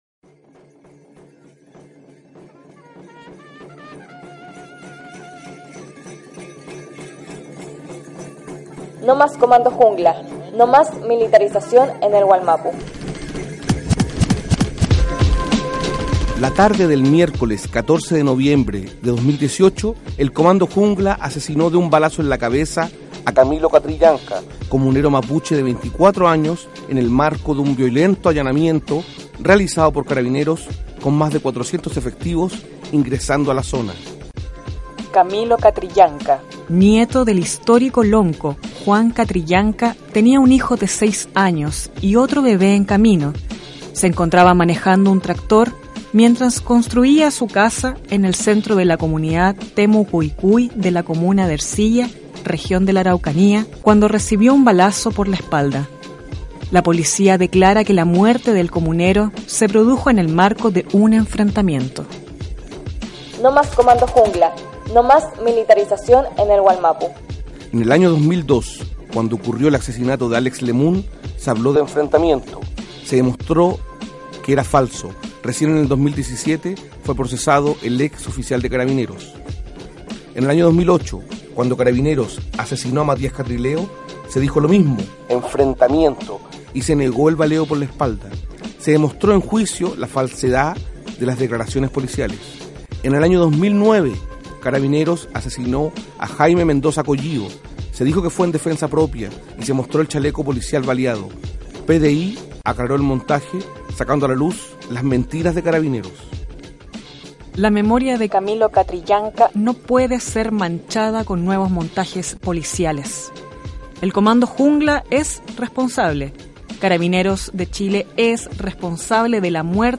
A continuación, extendemos la campaña radial: «NO + Comando Jungla», material radiofónico de concientización sobre la violencia y el dolor que la policía militarizada está dejando en las comunidades Mapuche en Wallmapu.